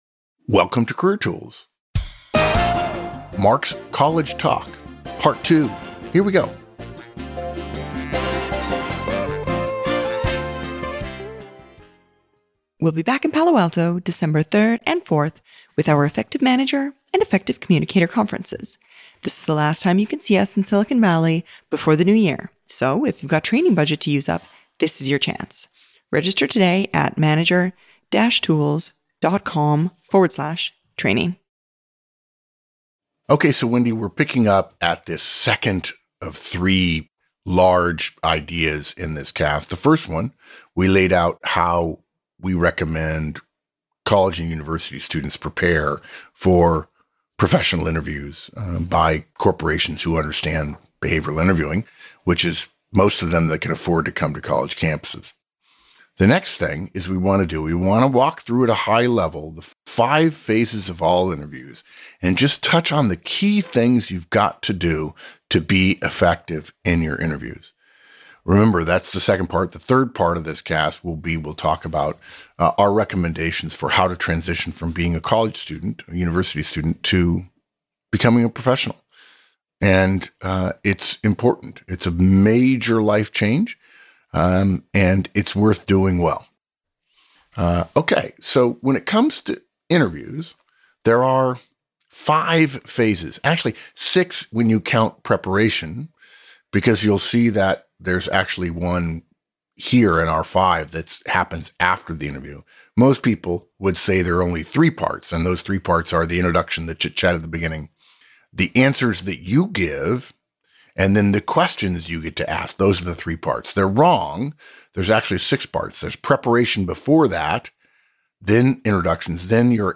College Talk